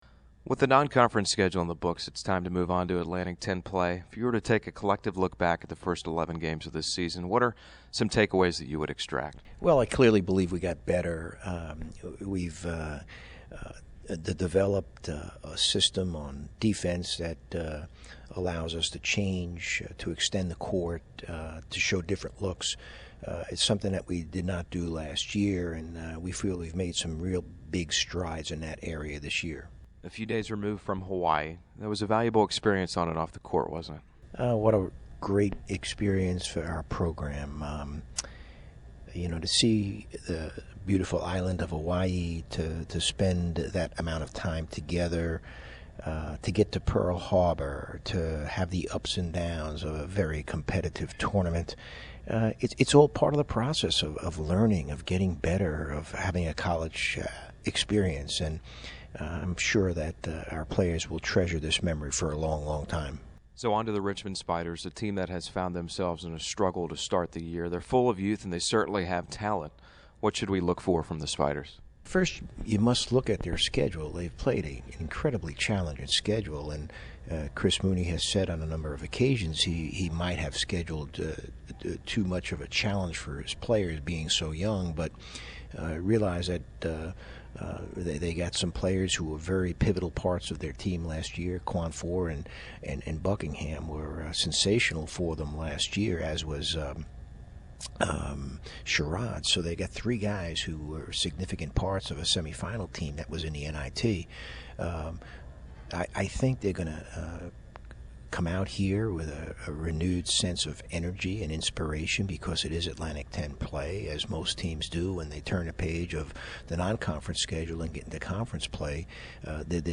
Pregame Interview